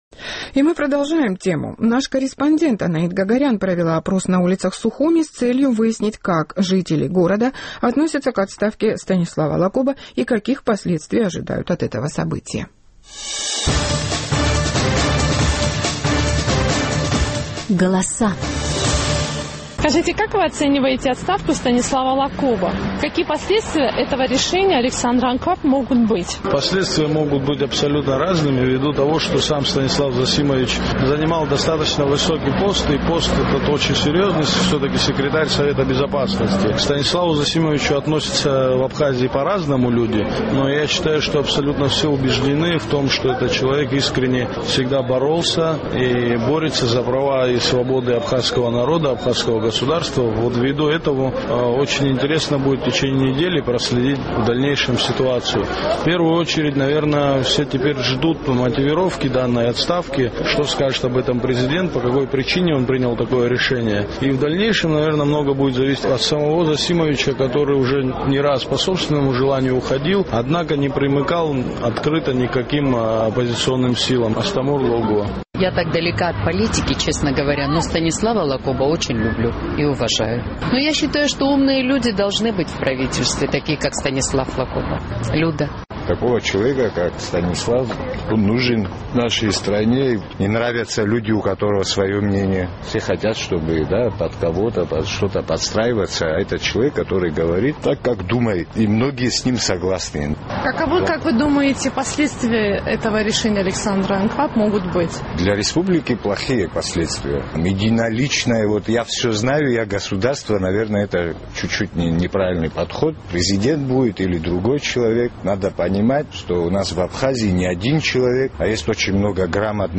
Наш абхазский корреспондент интересовалась мнением жителей Сухума по поводу отставки секретаря Совета безопасности Абхазии Станислава Лакоба.